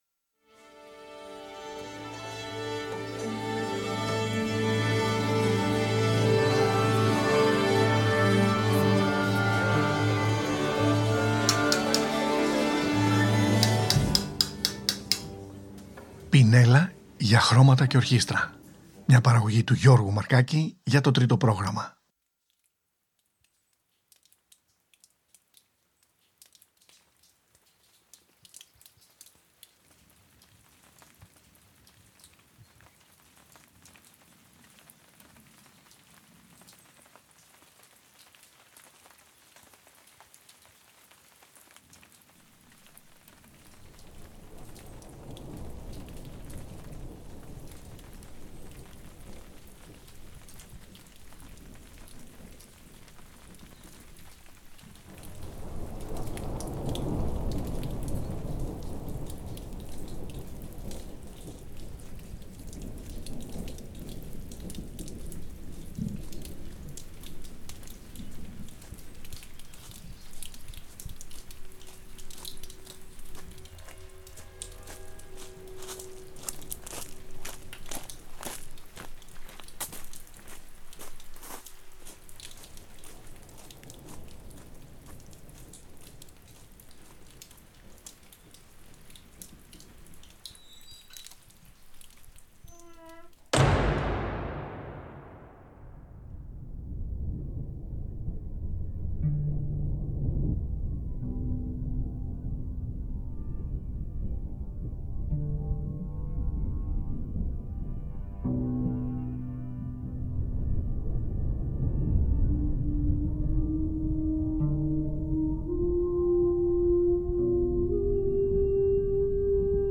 τρομπετίστα